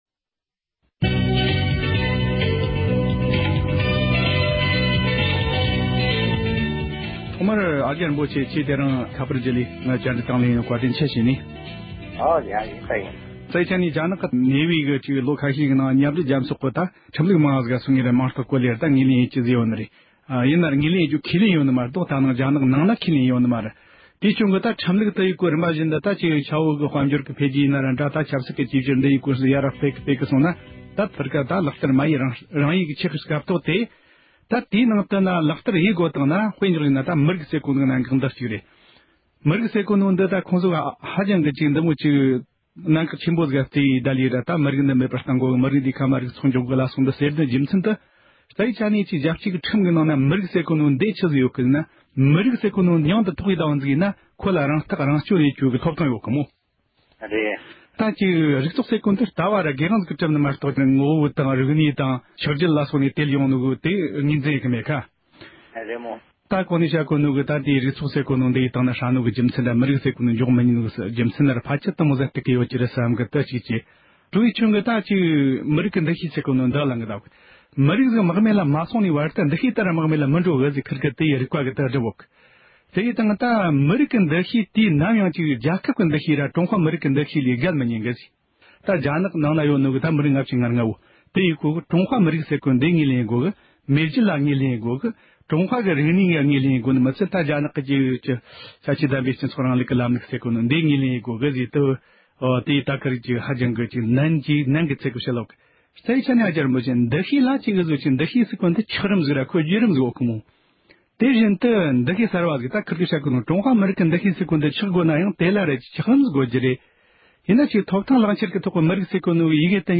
རྒྱ་ནག་འཐབ་ཕྱོགས་གཅིག་གྱུར་ལྷན་ཁང་གི་བློན་གཞོན་པས་སྤེལ་བའི་ཆེད་བྲིས་ཐད་ཨ་ཀྱྰ་རིན་པོ་ཆེ་མཆོག་དང་བགྲོ་གླེང་ཞུས་པ།